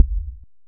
impactMetal_001.ogg